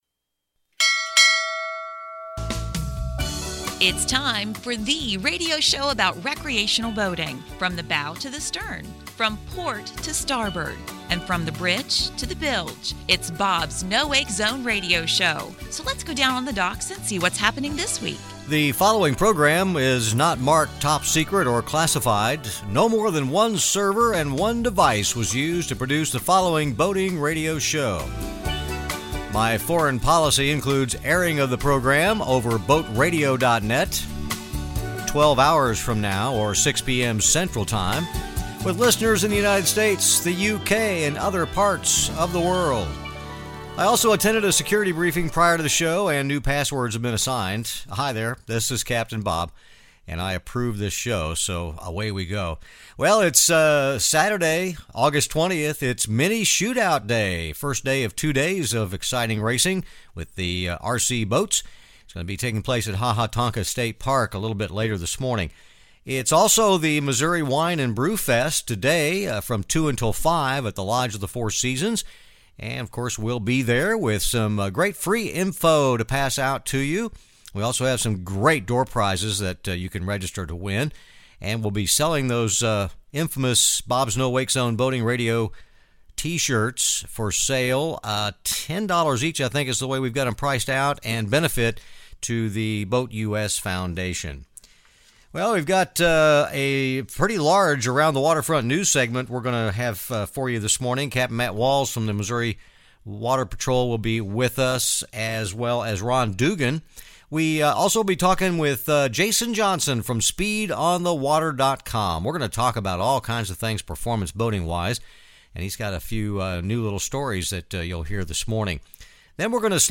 More boating mayhem from the Lake of the Ozarks in Missouri.
This week it’s Mini Shoot Out Day at Ha Ha Tonka State Park (that’s radio controlled boats, to you and me).
This is THE recreational boating radio show.